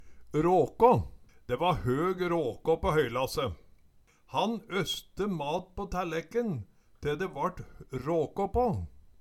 Høyr på uttala Ordklasse: Substantiv hankjønn Attende til søk